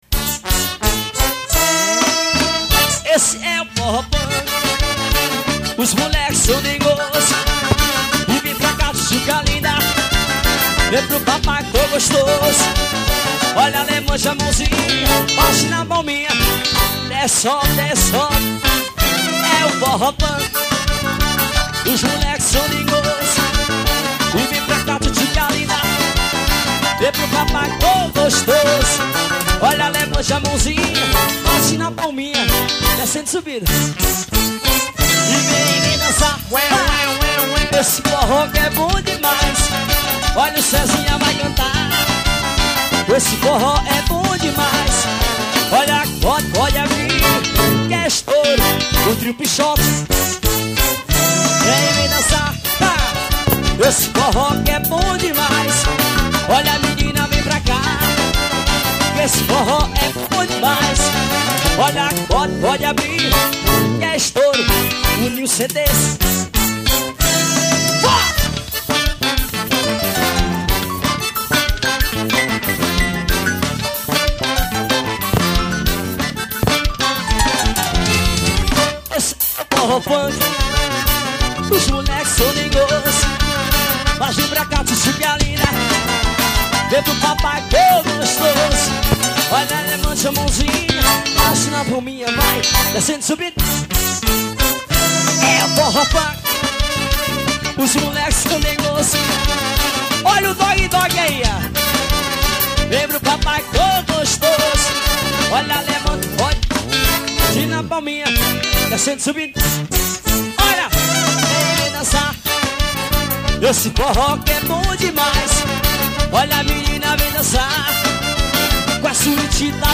EstiloForró